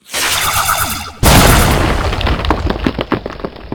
laser1.ogg